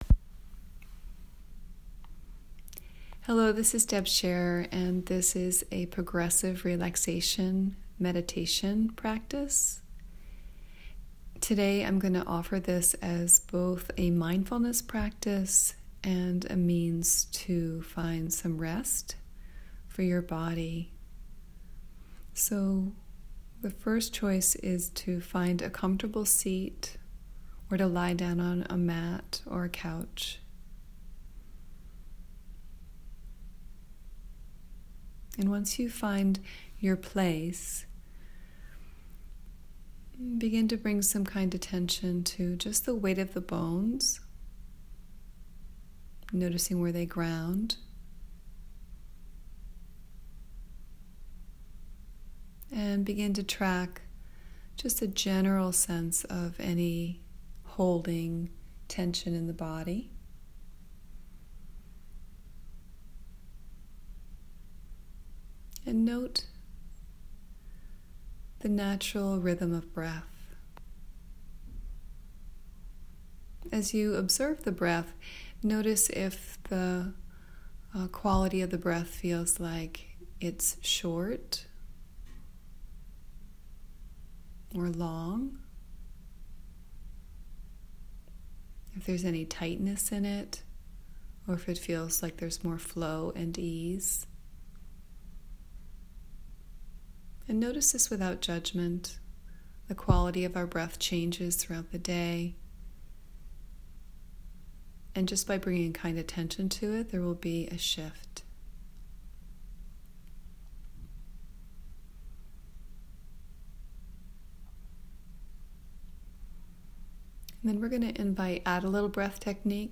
progressive relaxation meditation